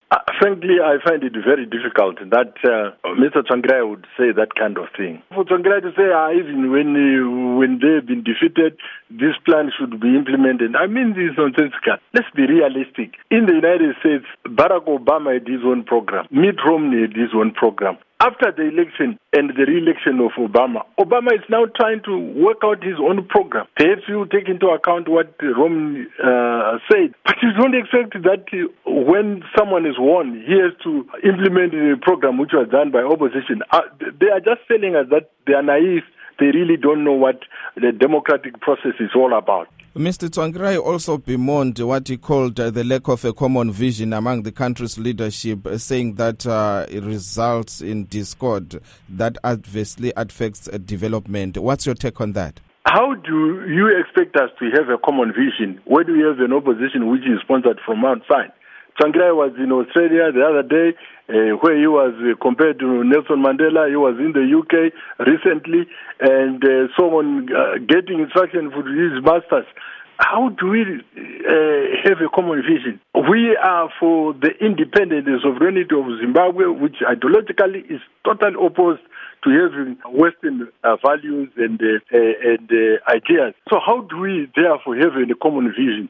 Interview With Rugare Gumbo